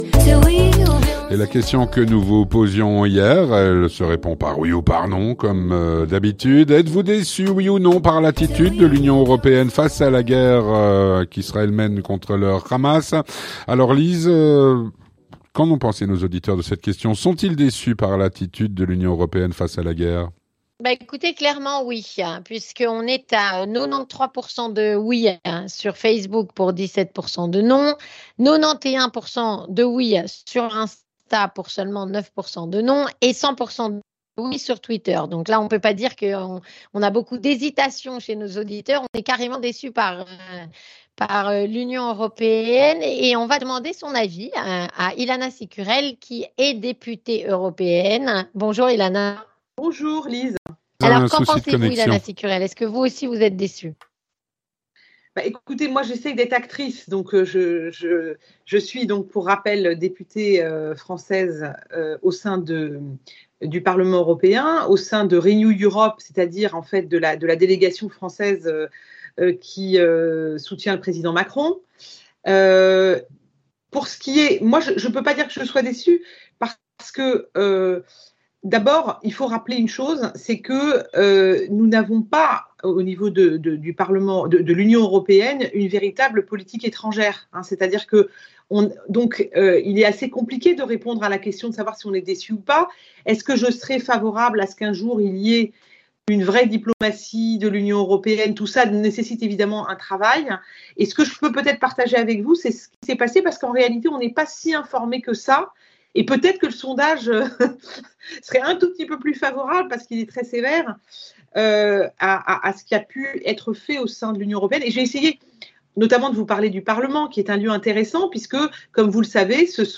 Ilana Cicurel, Députée européenne (Renew), répond à "La Question Du Jour"